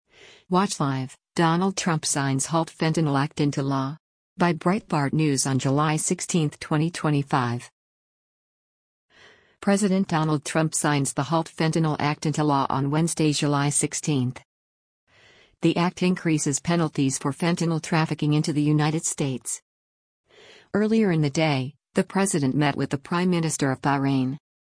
President Donald Trump signs the HALT Fentanyl Act into law on Wednesday, July 16.